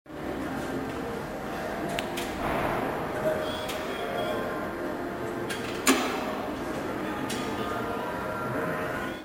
dispersion_drop.mp3